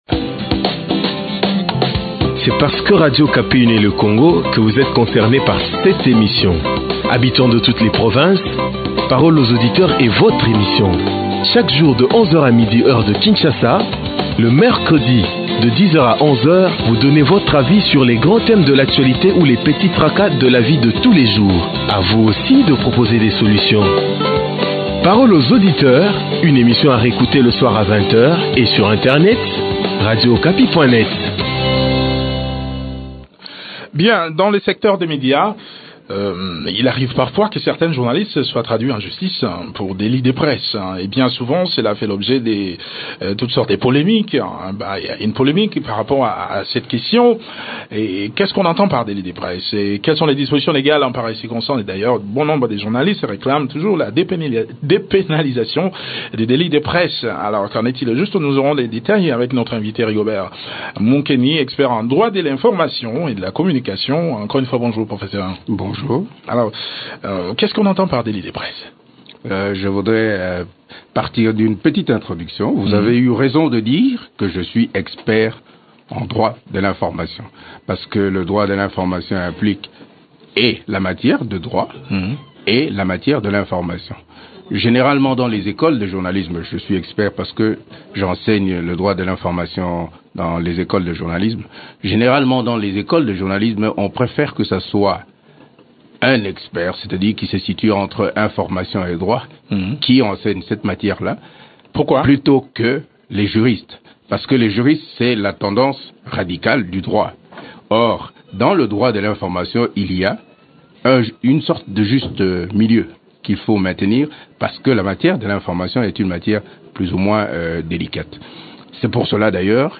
expert en droit de l’information et de la communication.